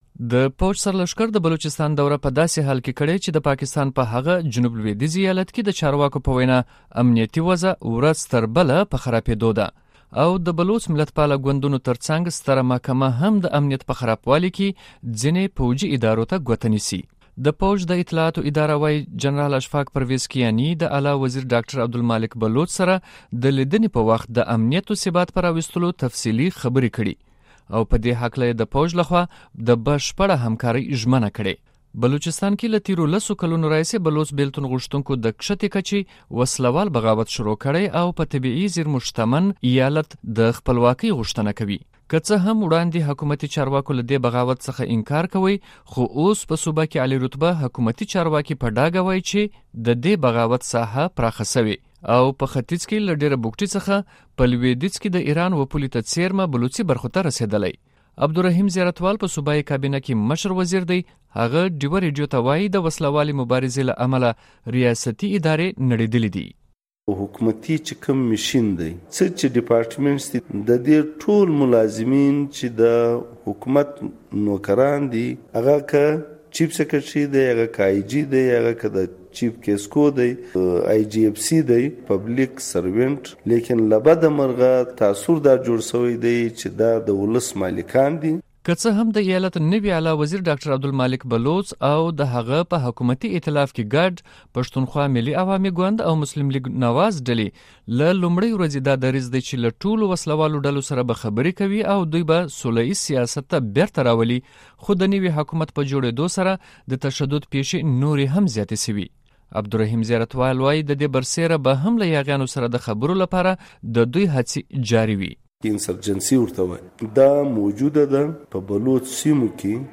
د وائس اف امریکا ډیوه ریډیو سره مرکه کې ښاغلي زیارت وال بلوچستان کې د امن او امان د نشتوالې په هکله وویل صوبه کې د بلوڅو په سېمو کې د ټیټې کچې انسرجنسي یا بعاوت روان دی او د حکومت کوشش دی چې د خبرو اترو د لارې د مسلې حل راویستلی شي